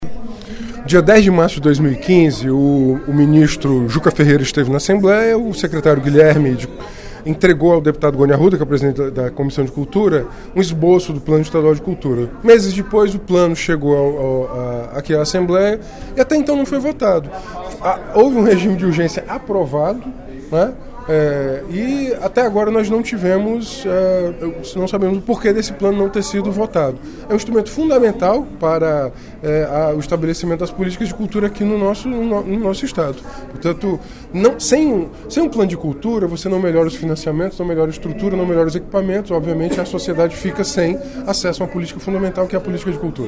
O deputado Renato Roseno (Psol) cobrou, durante o primeiro expediente da sessão plenária da Assembleia Legislativa desta quarta-feira (02/03), a aprovação do Plano Estadual de Cultura.